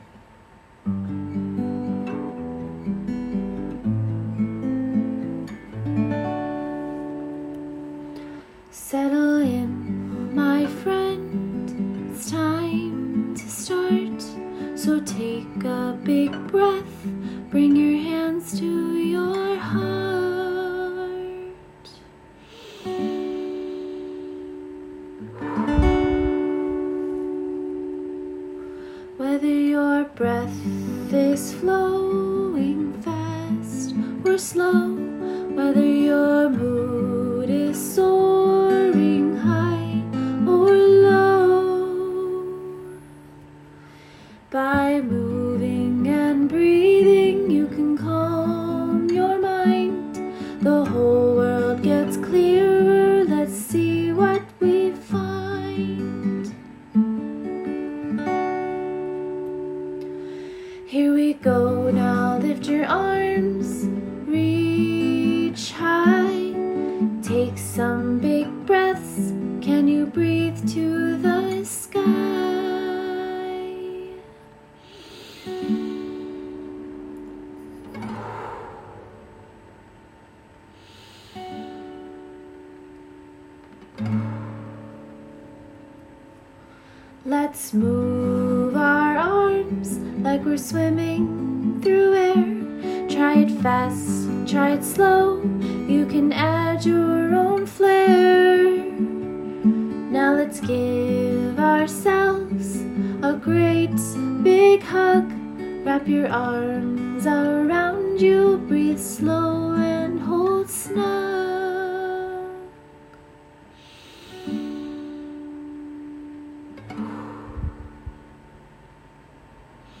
has written a melody to use along with this book in order to bring in the element of music to assist relaxation.